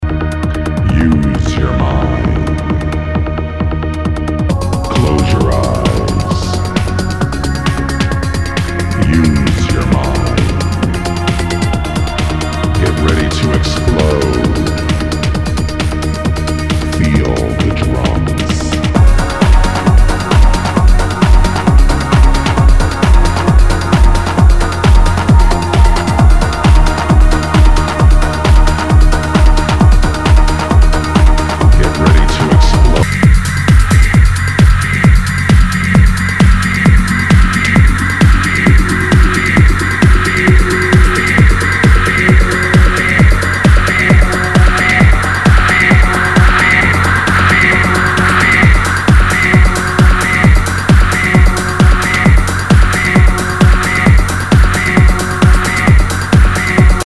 HOUSE/TECHNO/ELECTRO
ナイス！トランス / プログレッシブ・ハウス！
全体にチリノイズが入ります